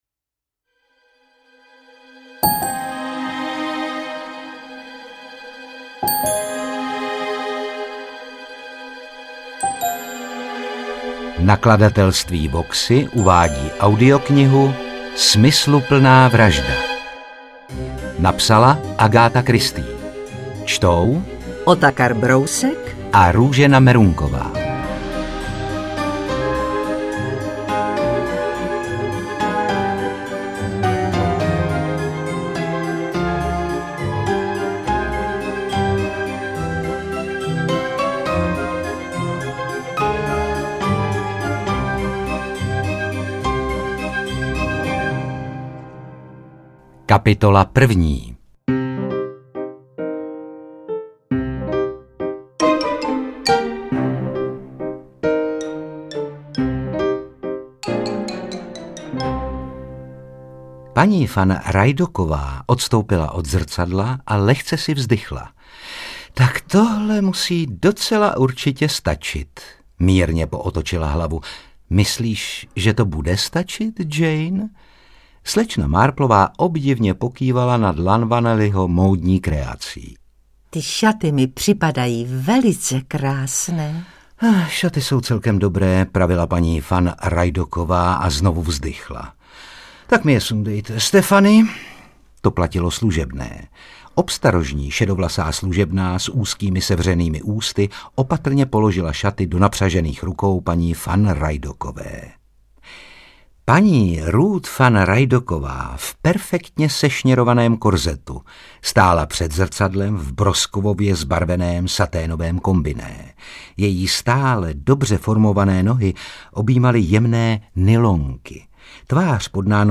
Interpreti:  Otakar Brousek ml., Růžena Merunková